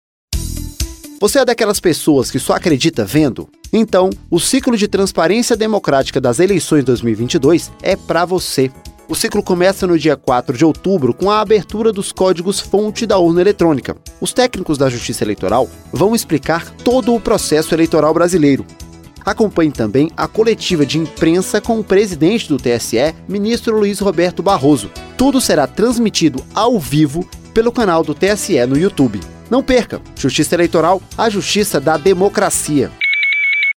Spot: Acompanhe a abertura do código-fonte da urna eletrônica